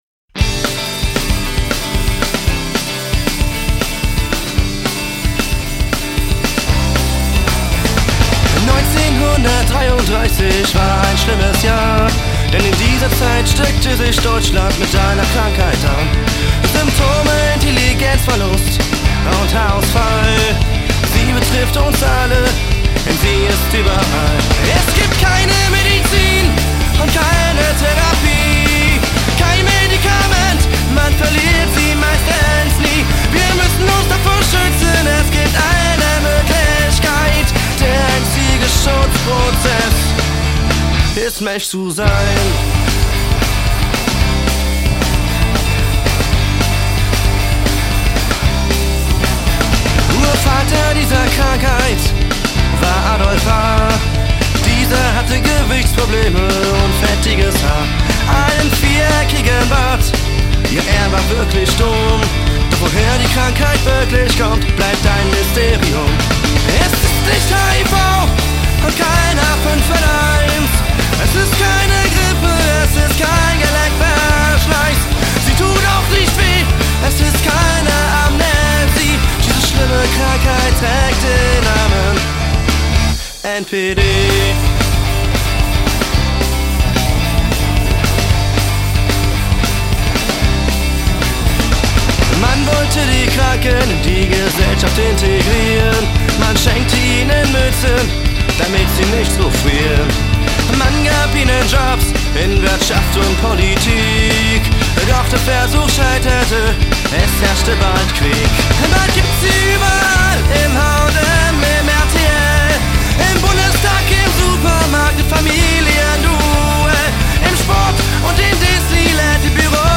Punk Rock, DeutschPunk, Skapunk
punk´n´ska´roll